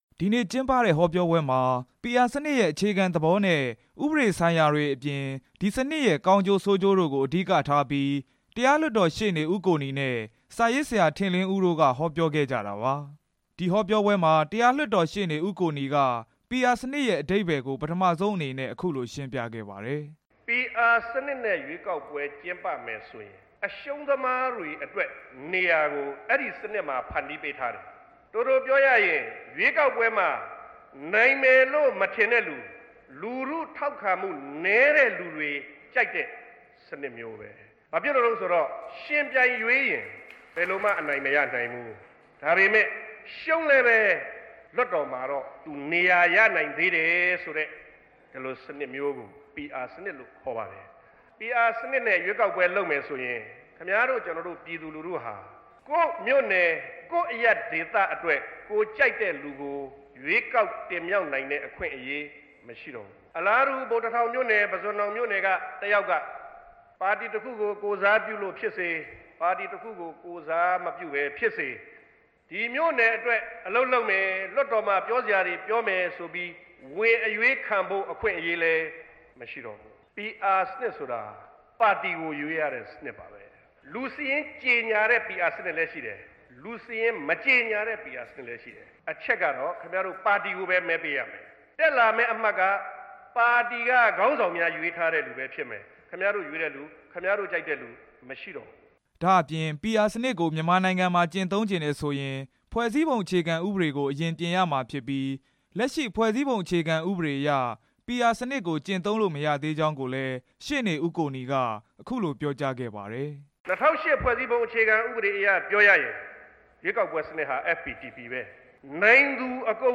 ဟောပြောပွဲအကြောင်း တင်ပြချက်